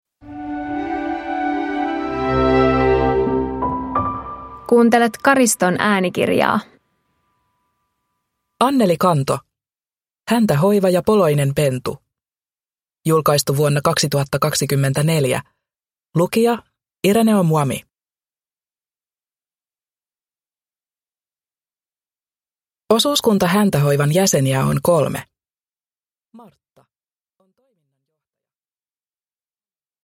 Häntähoiva ja poloinen pentu – Ljudbok